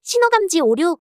audio_traffic_error.wav